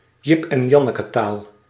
Ääntäminen
IPA: /ˌjɪp ɛn ˈjɑnəkətaːl/